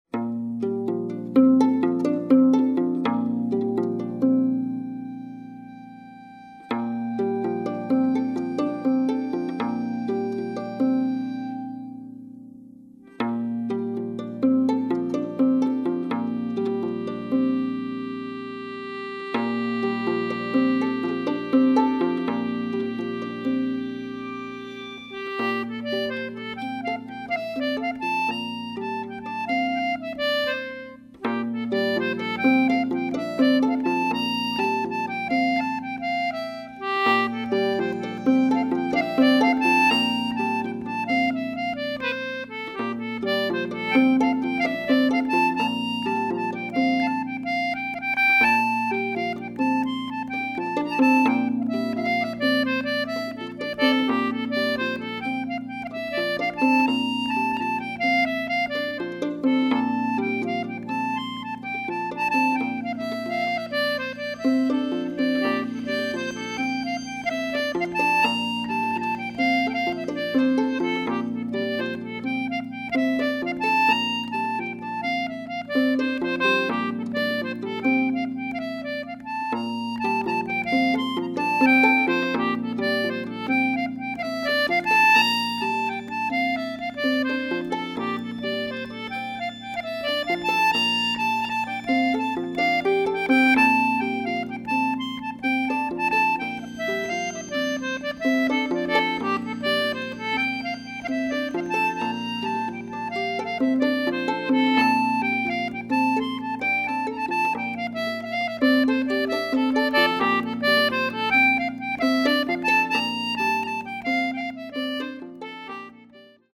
a single concertina line